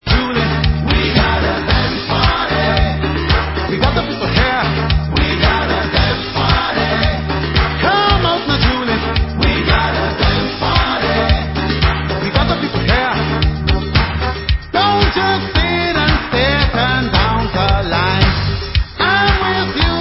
World/Reggae